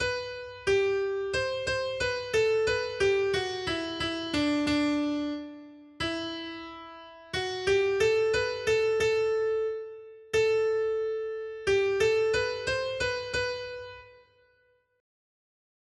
responsoriální žalm